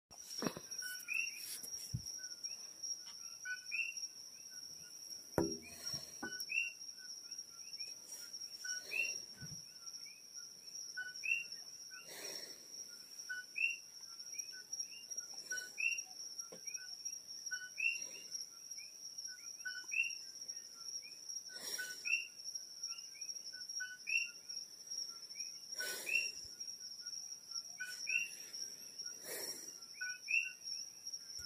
Die Geräuschkulisse ist das Gegenteil zu unserer Wohnung in Maui an der vielbefahrenen Straße. Die Vögel singen, Grashüpfer Zirpen und Frösche quaken, ständig knackt es irgendwo, durch die riesigen Fenster bekommt man alles gut mit.